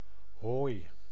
Ääntäminen
Synonyymit argent Ääntäminen France: IPA: [lə fwɛ̃] Tuntematon aksentti: IPA: /fwɛ̃/ Haettu sana löytyi näillä lähdekielillä: ranska Käännös Ääninäyte Substantiivit 1. hooi {n} Suku: m .